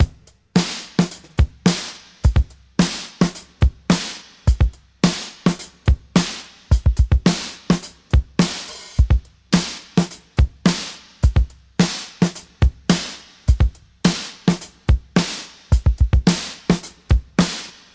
107 Bpm Drum Groove D# Key.wav
Free drum beat - kick tuned to the D# note. Loudest frequency: 1676Hz
107-bpm-drum-groove-d-sharp-key-4N8.ogg